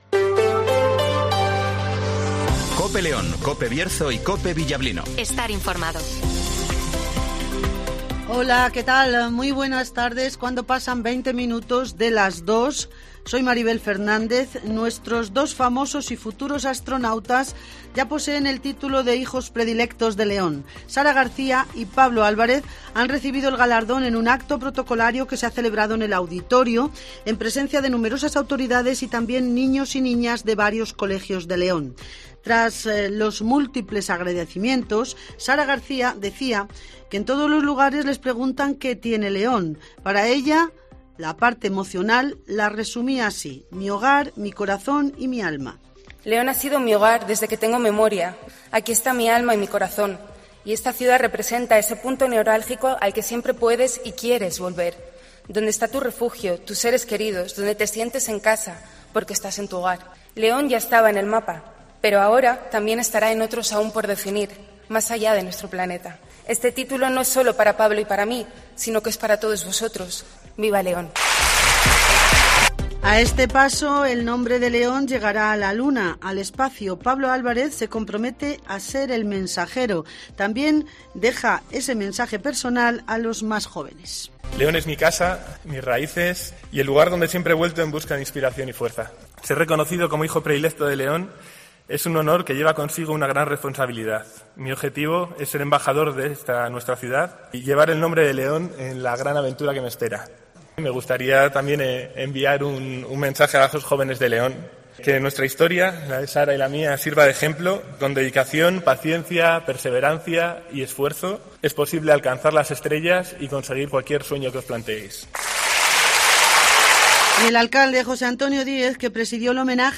INFORMATIVOS
Repaso a la actualidad informativa de León capital, del Bierzo y del resto de la provincia. Escucha aquí las noticias con las voces de los protagonistas.